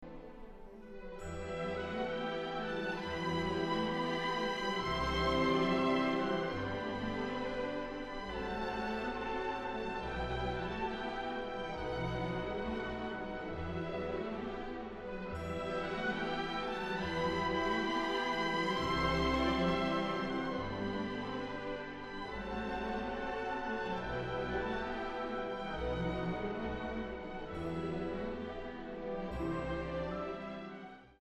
Dat wil zeggen: de muziek is beschrijvend, vertellend, anekdotisch.
Luister naar een fragment van deze muziek van Smetana: de verbeelding van een rustig voortkabbelende rivier:
Die Moldau_rustig.mp3